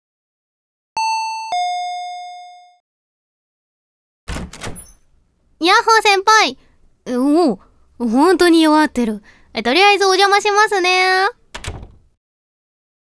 やさしい声は、いちばん効くおくすり💊
ボイスサンプル